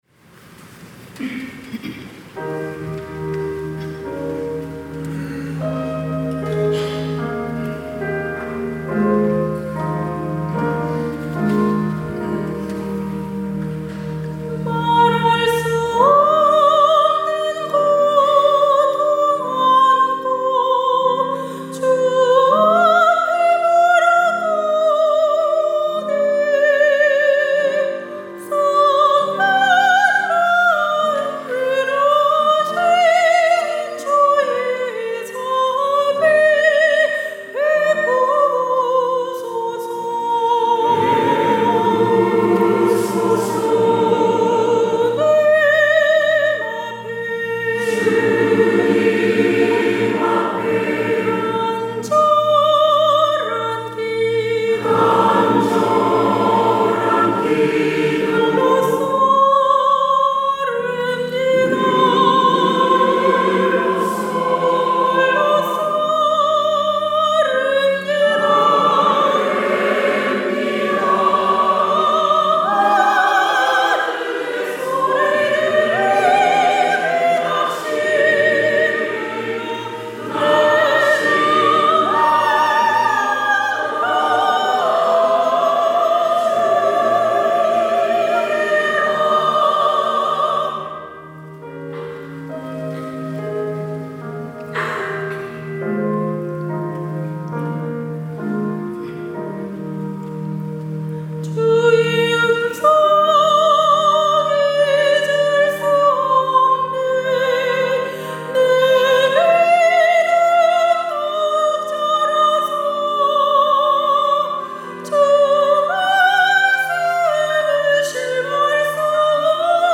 시온(주일1부) - 구속자에게 드리는 찬미
찬양대